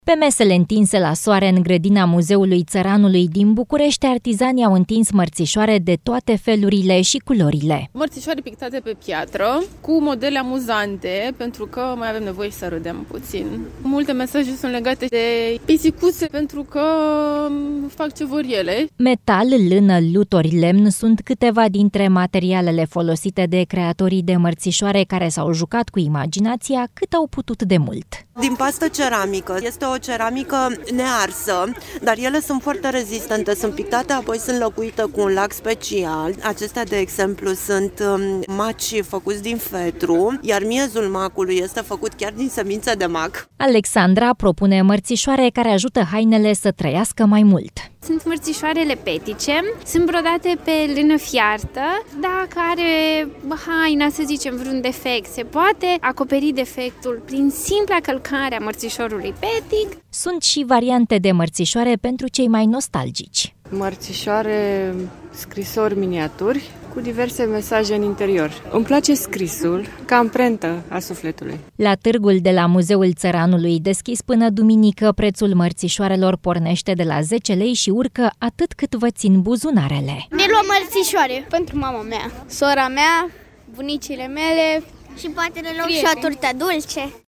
Meșterii populari veniți la târgul de la Muzeul Țăranului Român propun anul acesta mărțișoare lucrate manual, din lână sau metal, pictate pe lemn sau pe lut ars.
Multe modele sunt legate de pisicuțe, pentru că acestea fac ce vor ele”, spune o comerciantă
„Acestea sunt maci făcuți din fetru, iar miezul macului este făcut chiar din semințe de mac”, explică o producătoare de mărțișoare